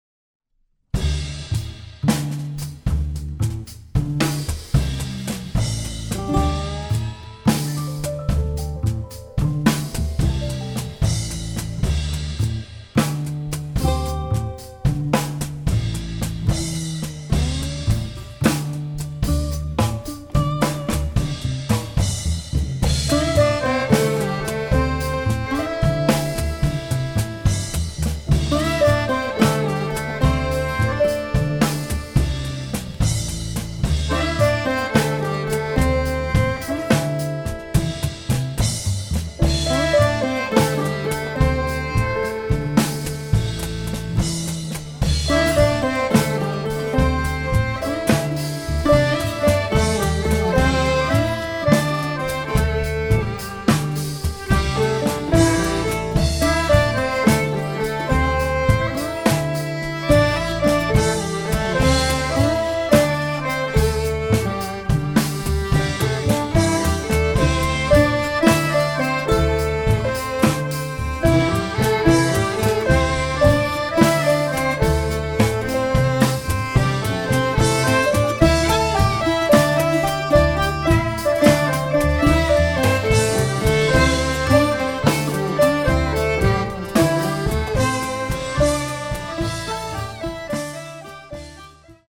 8 long tracks :  some mixed meter.
Drums, cajon, bass, piano, bouzouki, dudek, violin